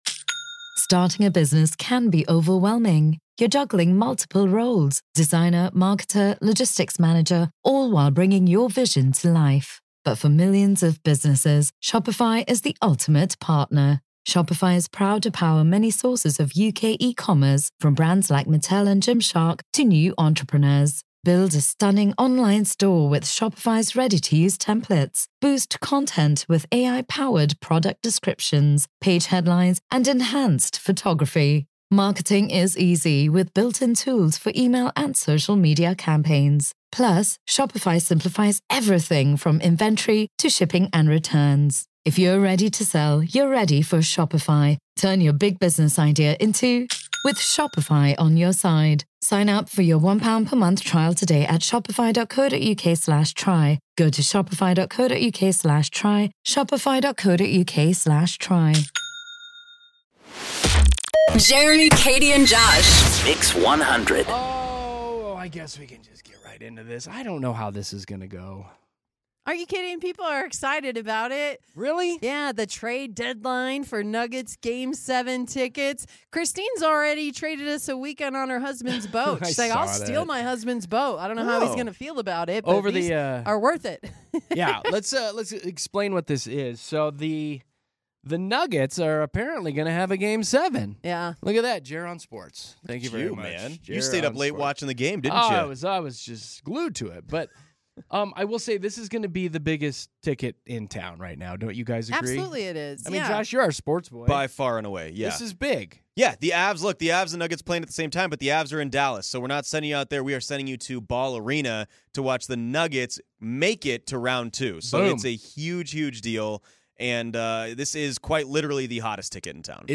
Denver's favorite morning radio show!